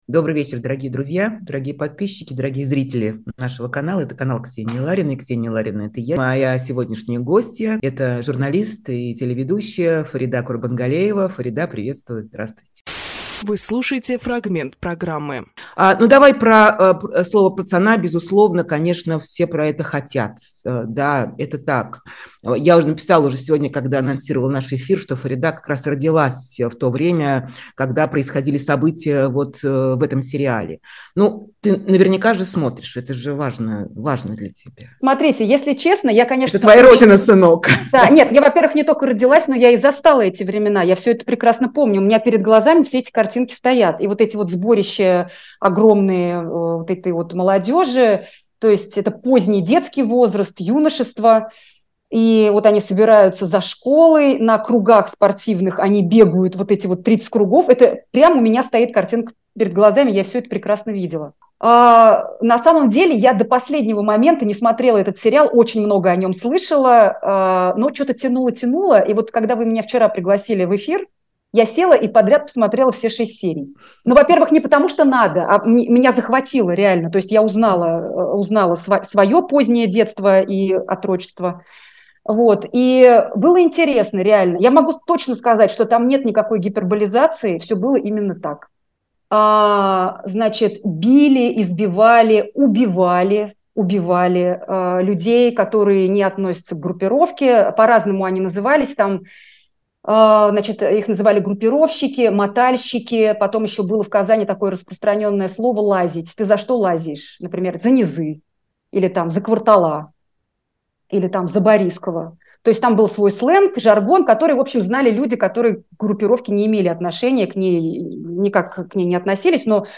Фрагмент эфира от 09.12.23